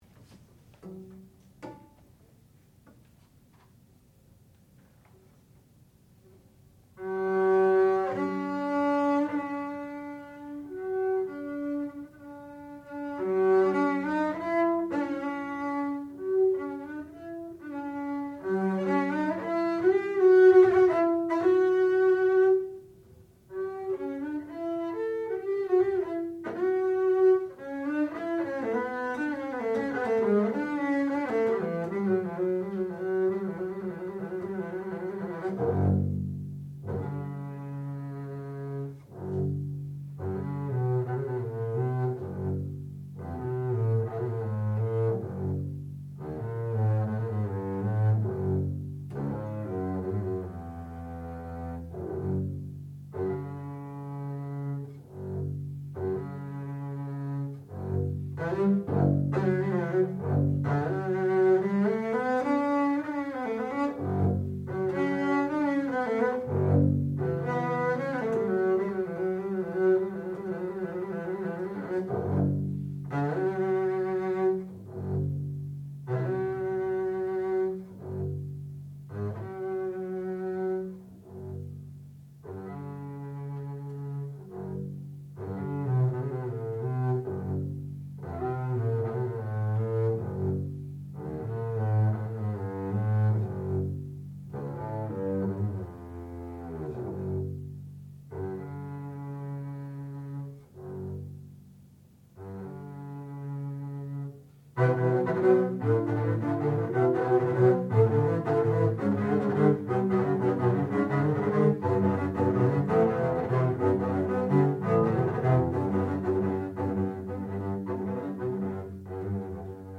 sound recording-musical
classical music
double bass
Qualifying Recital